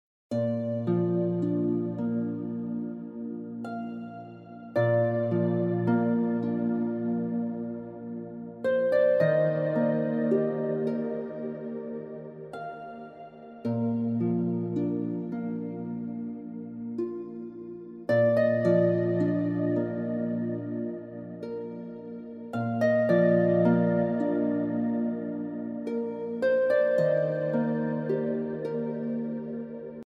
Heavenly Harp Music